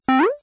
stage_change.wav